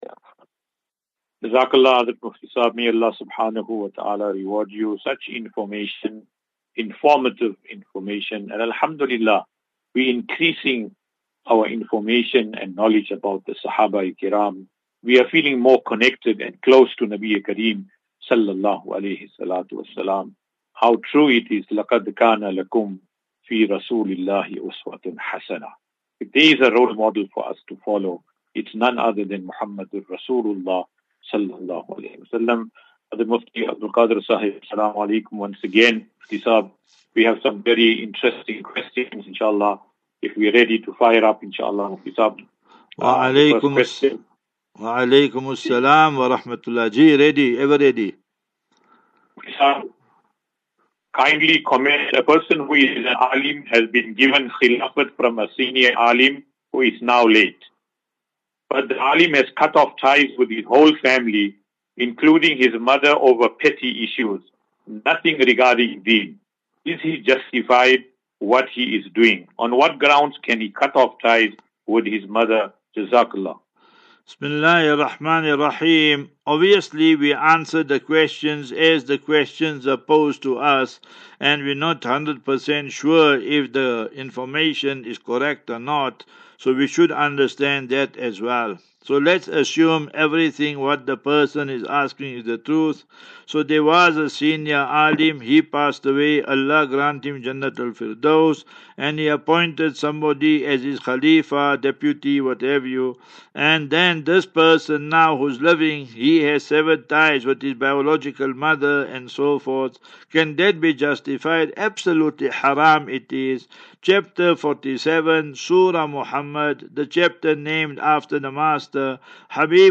As Safinatu Ilal Jannah Naseeha and Q and A 27 Mar 27 March 2024.